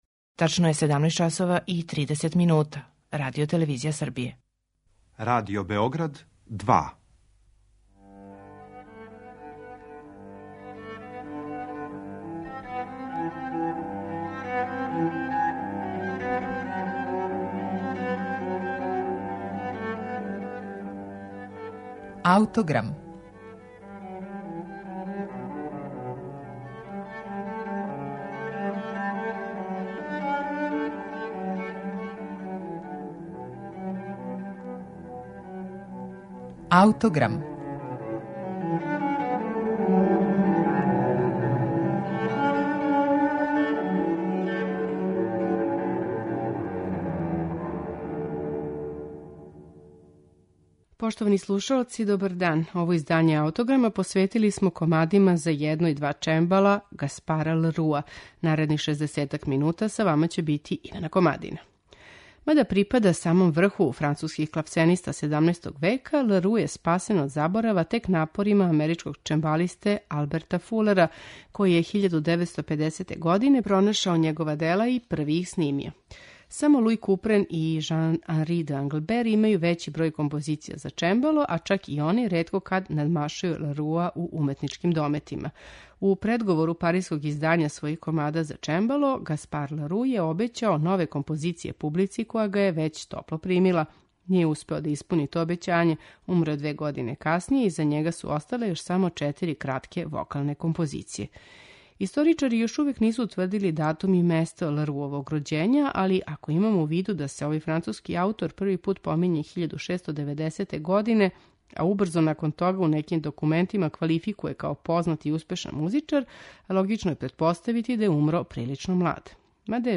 Слушаћемо комаде за чембало, Гаспара ле Руа
Иза овог композитора остала је само једна збирка комада за једно и два чембала, груписаних у седам целина које су повезане заједничким тоналитетом.